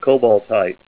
Help on Name Pronunciation: Name Pronunciation: Cobaltite + Pronunciation
Say COBALTITE Help on Synonym: Synonym: ICSD 31189   PDF 42-1345